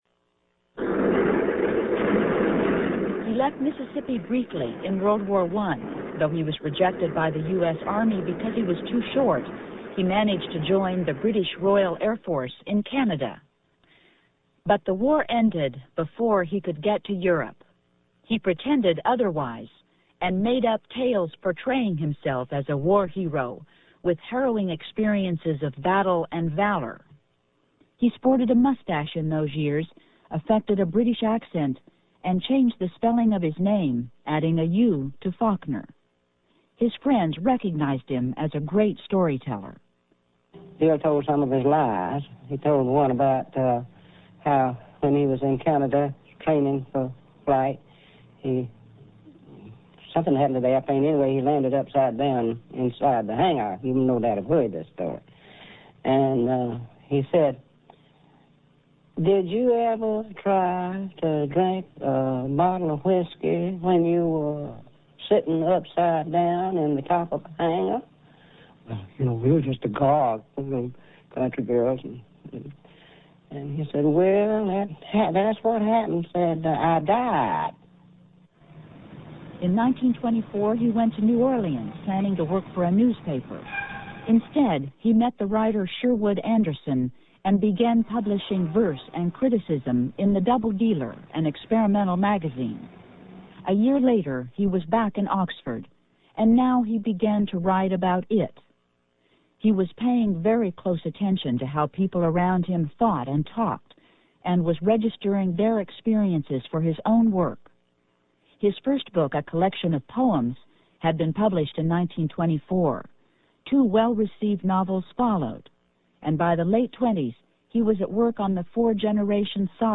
PBS, la chaîne de télévision publique américaine, le 26 septembre 1997
Biographie de William Faulkner, suivie de l'interview de la romancière Lee Smith